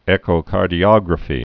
(ĕkō-kärdē-ŏgrə-fē)